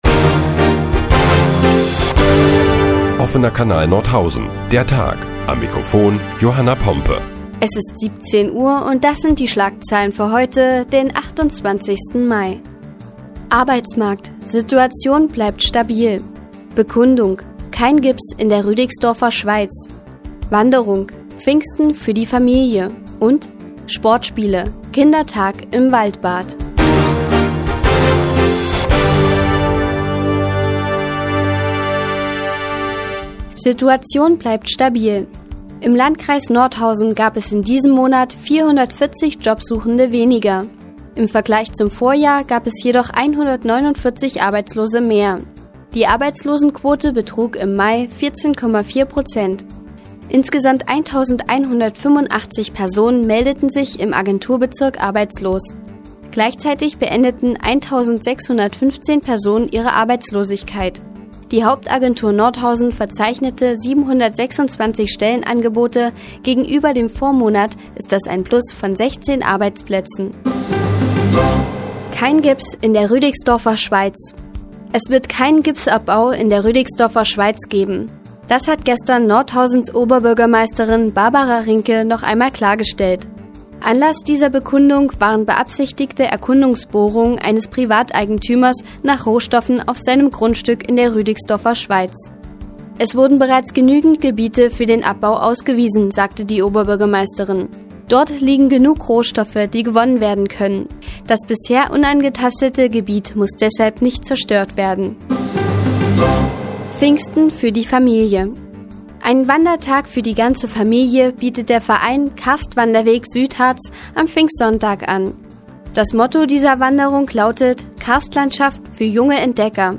Die tägliche Nachrichtensendung des OKN ist nun auch in der nnz zu hören. Heute geht es unter anderem um die Arbeitslosenquote im Mai und um den internationalen Kindertag im Waldbad.